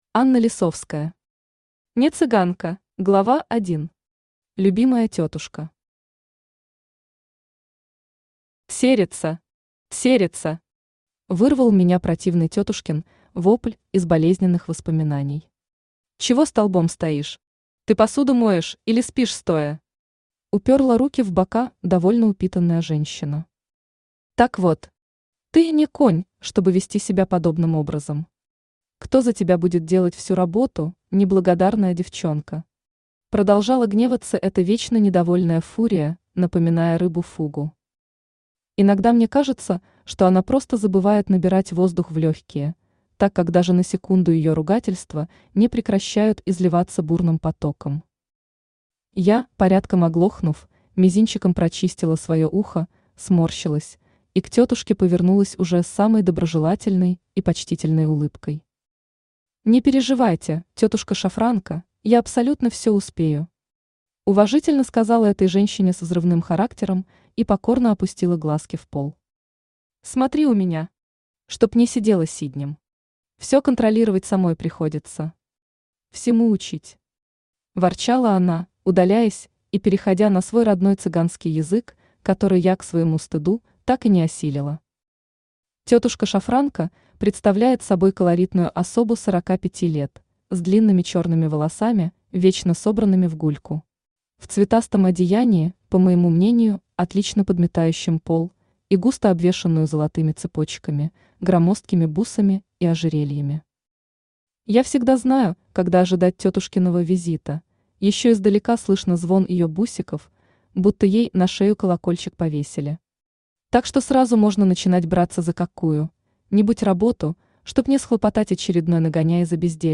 Аудиокнига Не цыганка | Библиотека аудиокниг
Aудиокнига Не цыганка Автор Анна Лисовская Читает аудиокнигу Авточтец ЛитРес.